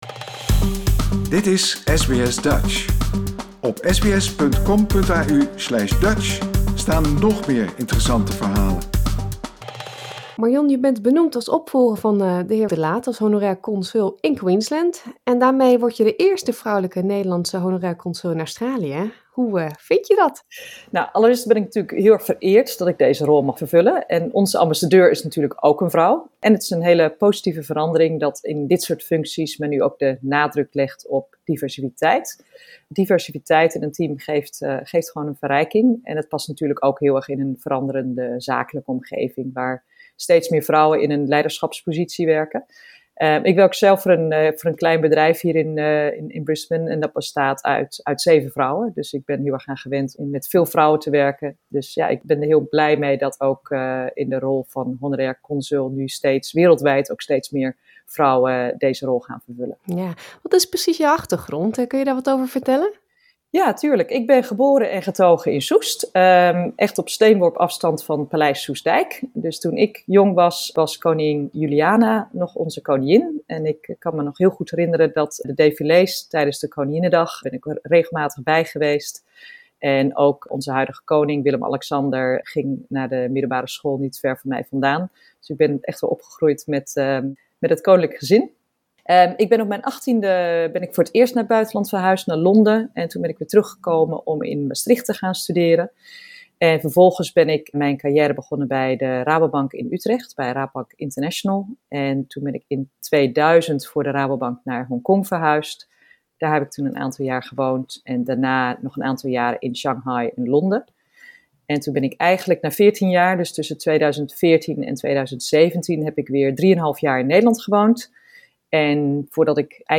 Zij begon deze week met haar erebaan en vertelde SBS Dutch over haar achtergrond, haar taken als H.C. en haar doelstellingen voor de komende jaren.